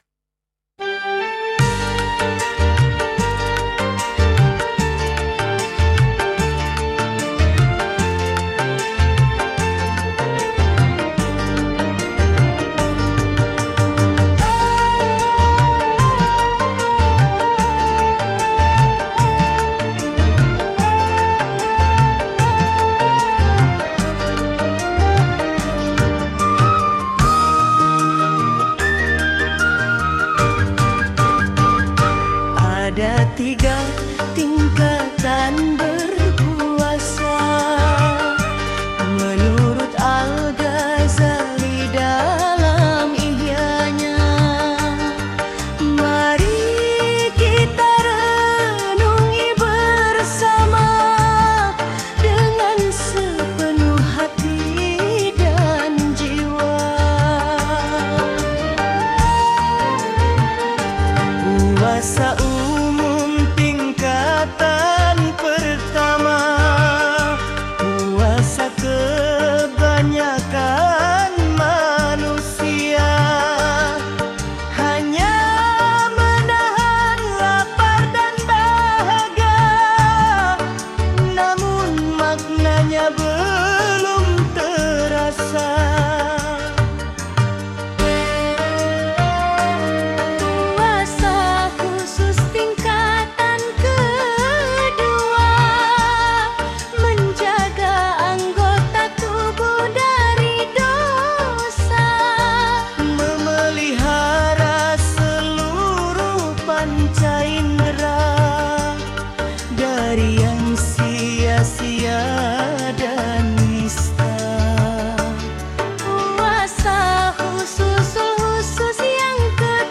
Dengarkan musiknya yang syahdu dan menyentuh syair tersebut!